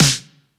SCOTT_STORCH_snare_big_80s.wav